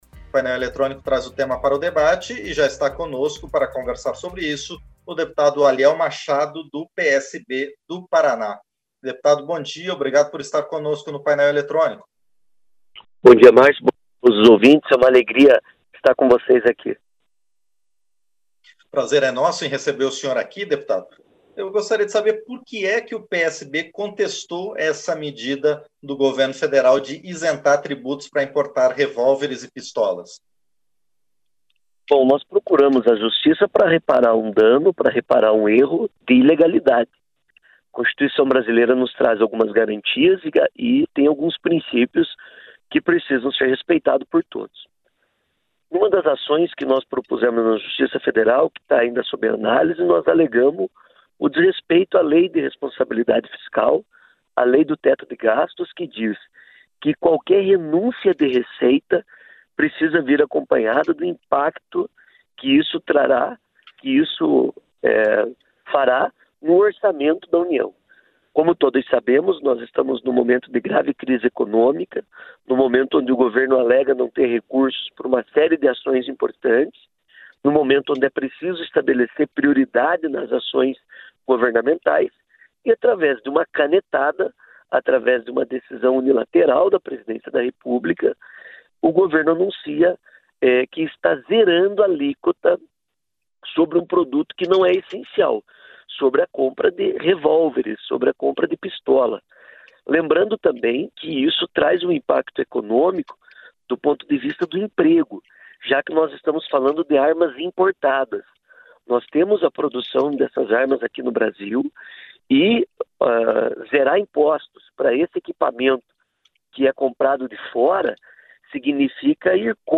Entrevista - Dep. Aliel Machado (PSB-PR)